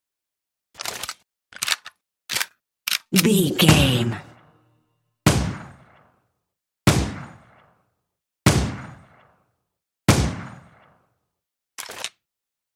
Filled with 10 sounds(44/16 wav.) of Pistol Equip, Clip eject, Insert, Reload, Firing(Five single shots) and Unequip.
Pistol Equip, Clip eject, Insert, Reload, Firing and Unequip 02
Sound Effects
Adobe Audition, Zoom h4
pistol